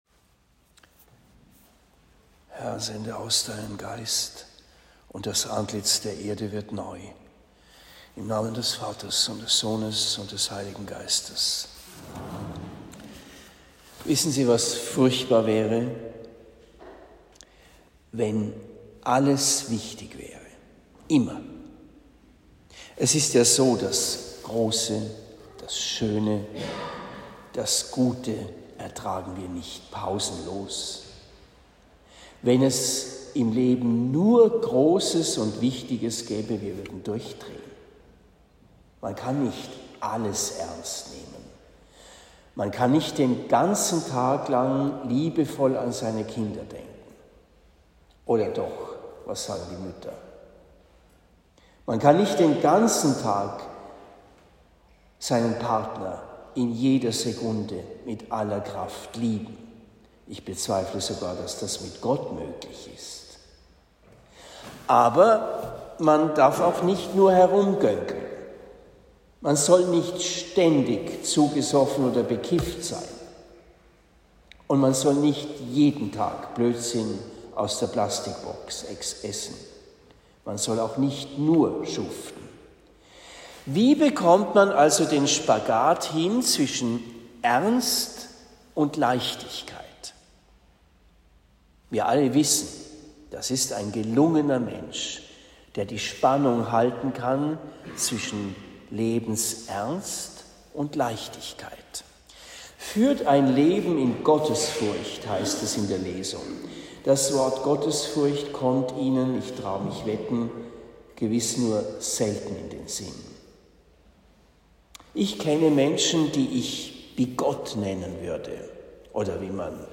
Predigt in Bischbrunn am 22. April 2023
Predigt in Rettersheim am 23.04.2023